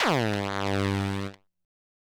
synth note04.wav